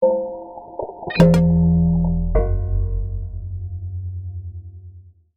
UI_SFX_Pack_61_58.wav